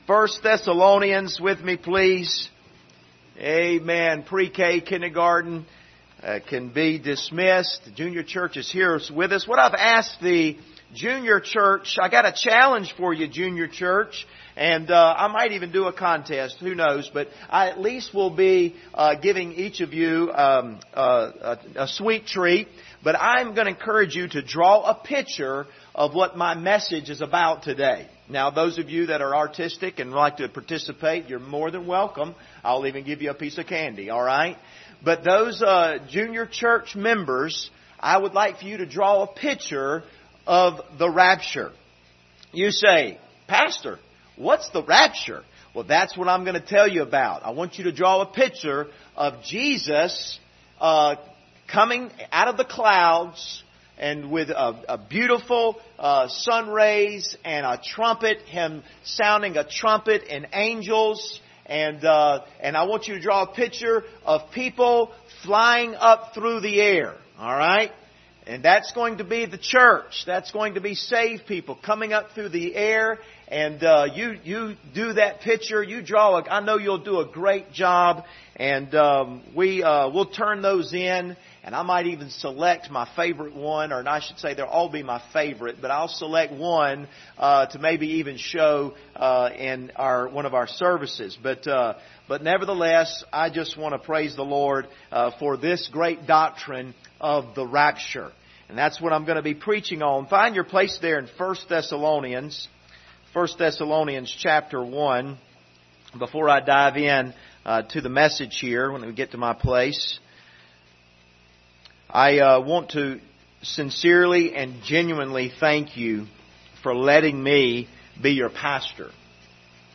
Service Type: Sunday Morning Topics: the rapture